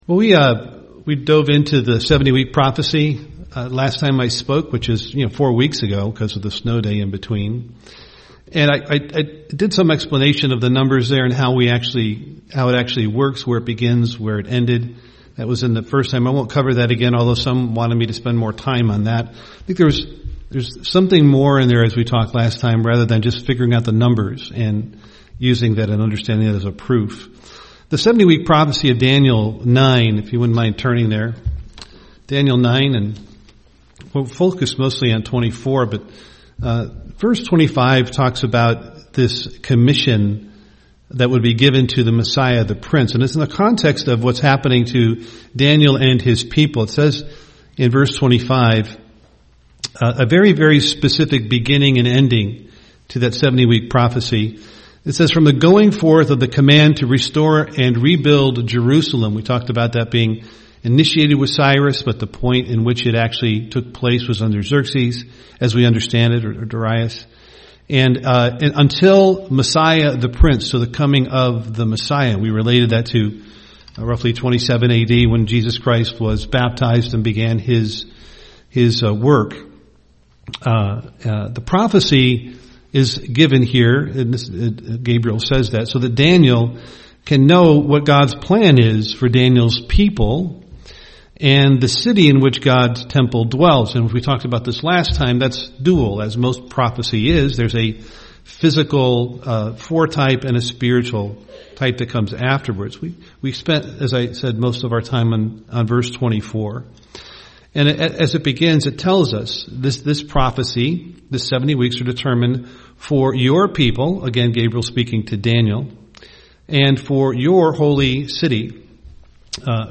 UCG Sermon Daniel prophecy Studying the bible?